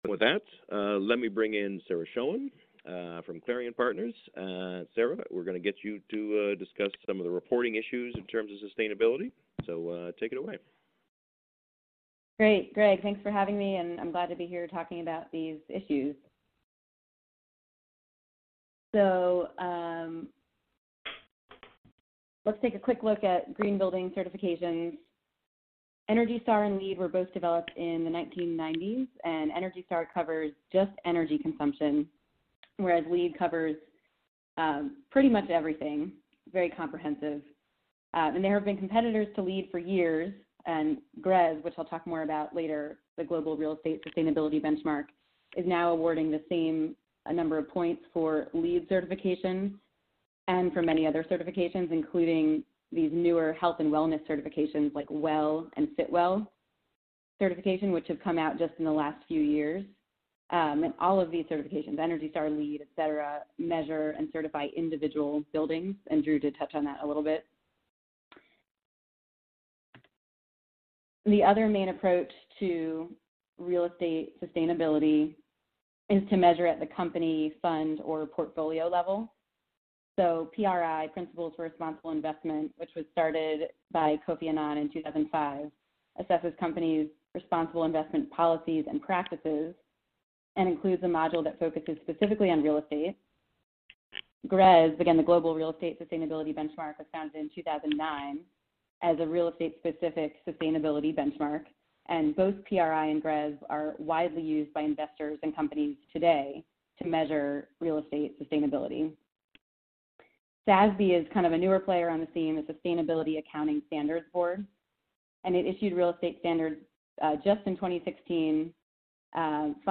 Pension Real Estate Association Webinar – Sustainability: What Do Investors Really Need to Know?